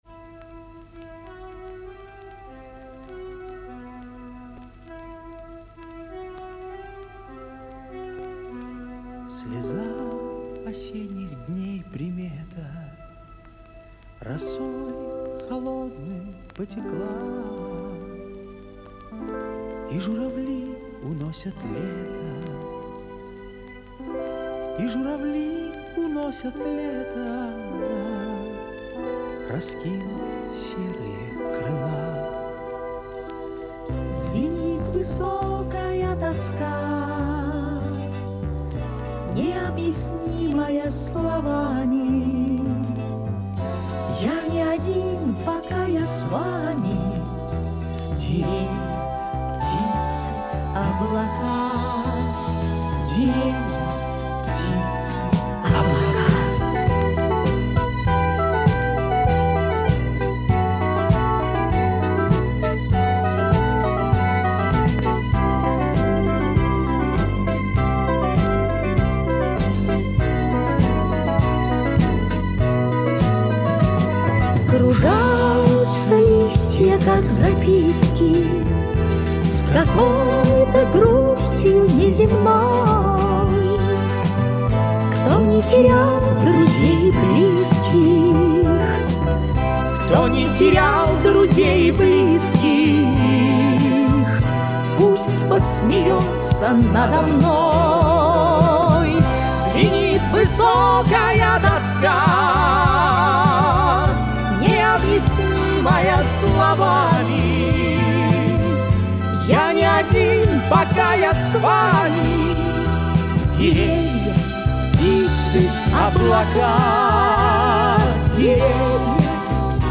инстр. анс. п.у.